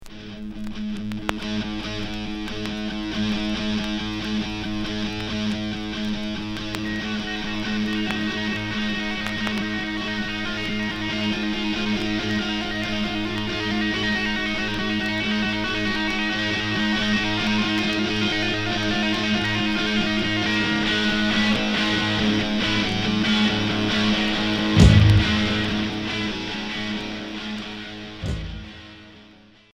Hard